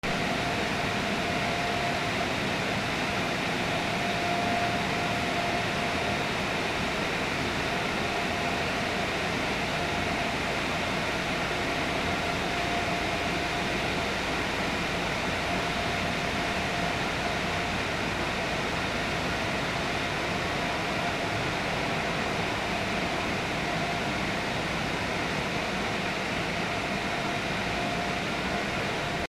ガソリンスタンド 洗車機 背景音
/ C｜環境音(人工) / C-30 ｜ファン・排気ダクト・空調音など